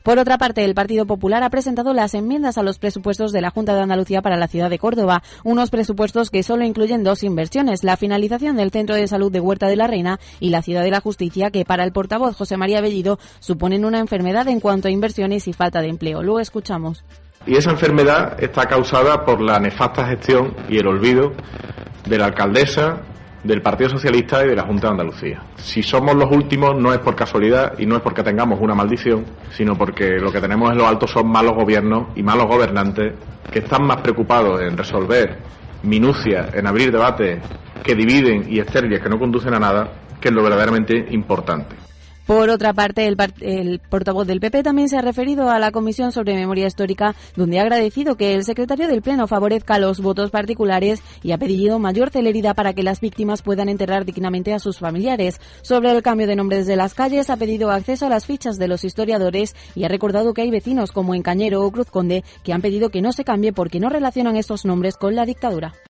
Escucha a José María Bellido